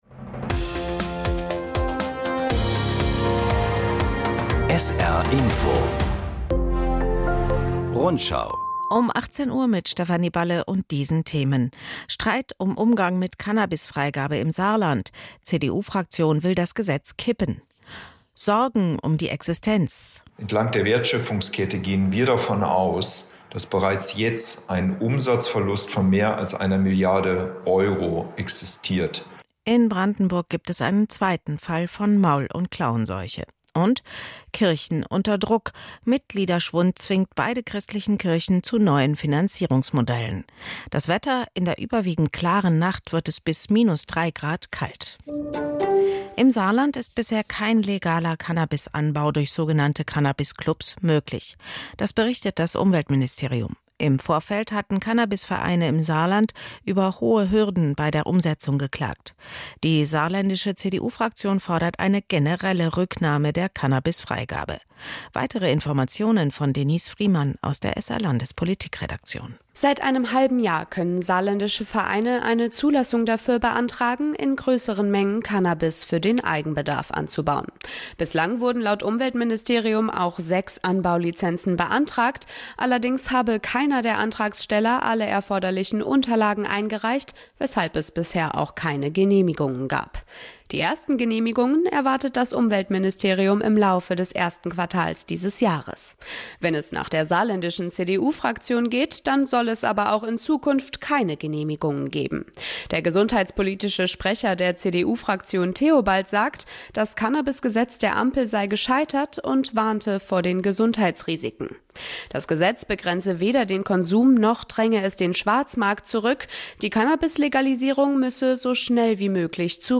… continue reading 5 ตอน # Nachrichten